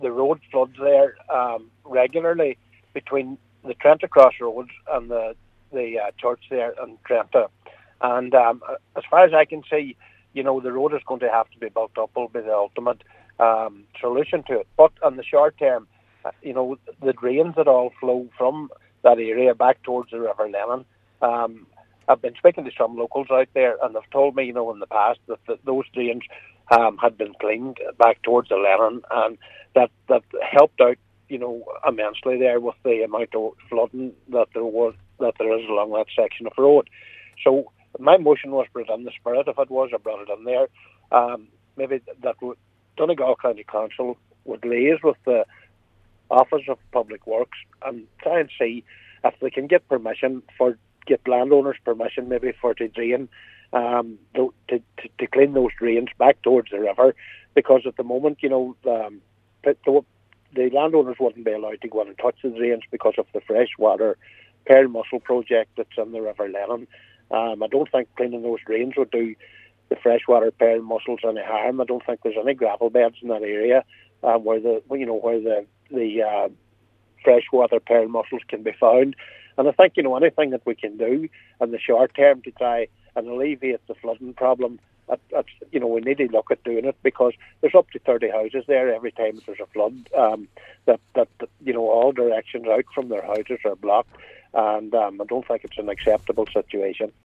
Councillor Michael McBride however, says additional efforts need to be made to put an end to flooding along the route from Trentagh crossroads to the local Church.